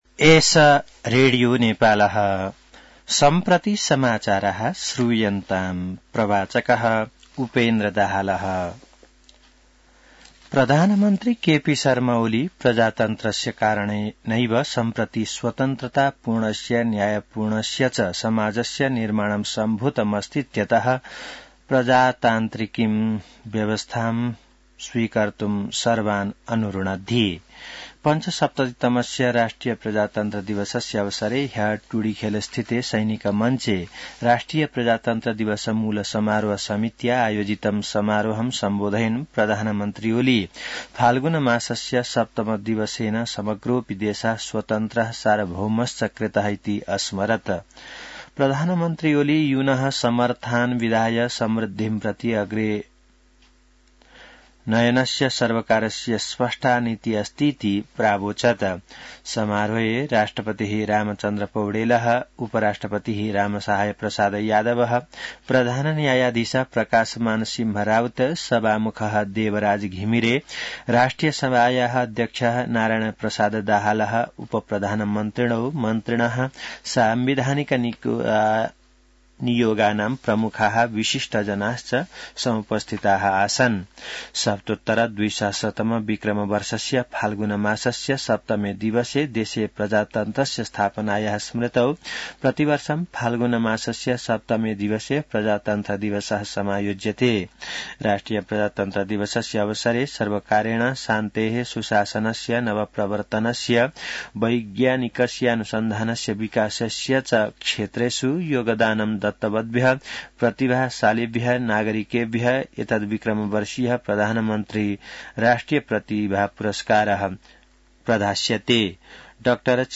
संस्कृत समाचार : ९ फागुन , २०८१